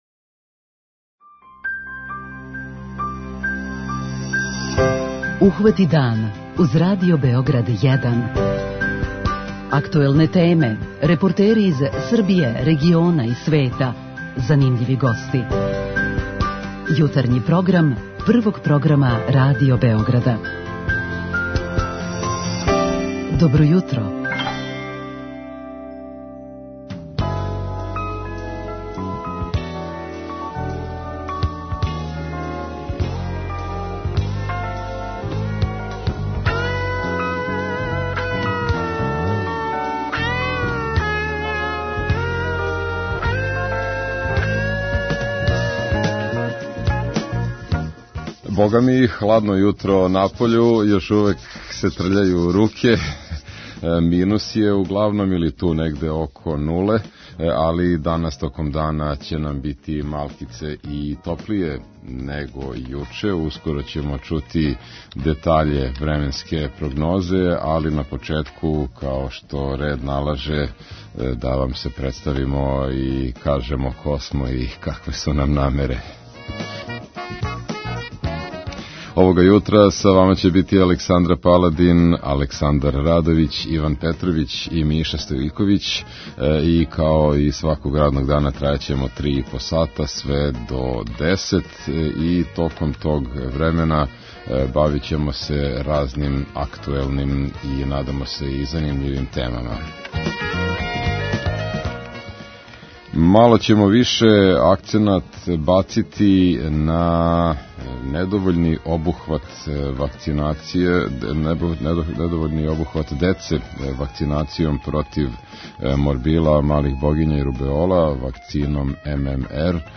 Претходно ћемо у редовној рубрици 'Питање јутра' о овој теми разговарати и с нашим слушаоцима.